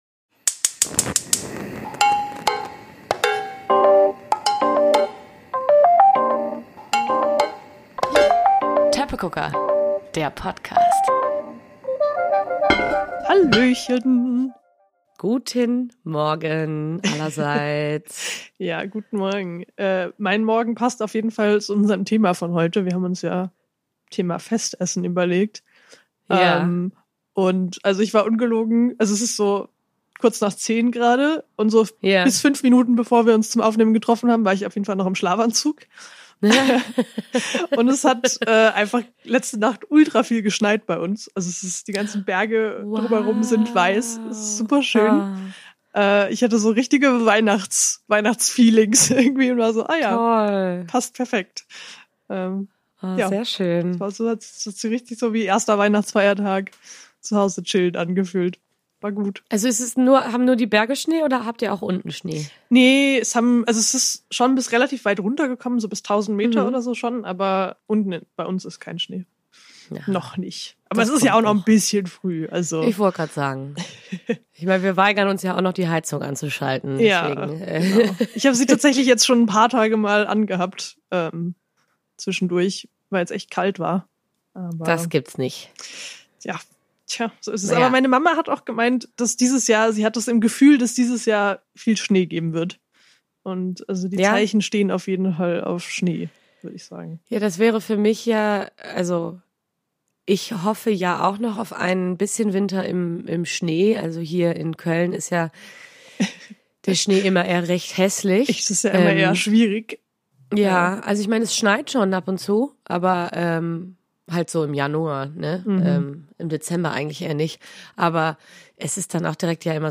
Und dieses mal sogar mit drei Stimmen anstatt nur zwei.